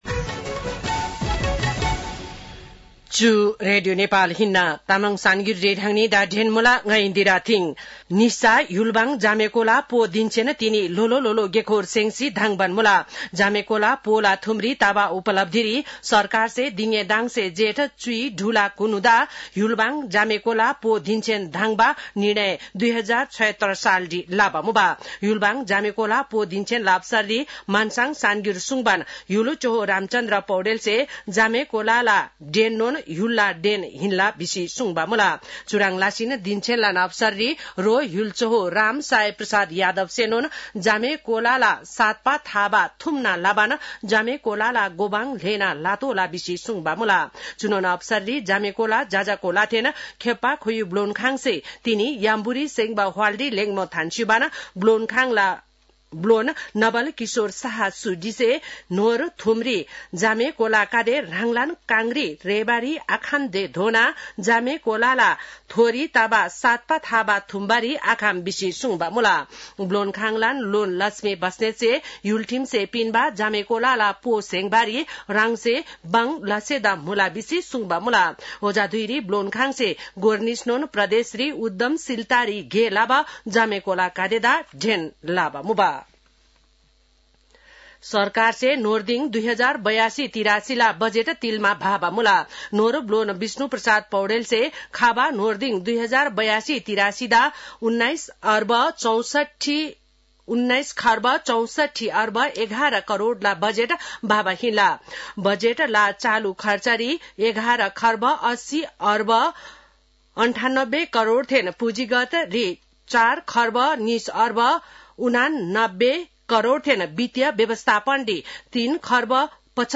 तामाङ भाषाको समाचार : १६ जेठ , २०८२
Tamang-news-2-16.mp3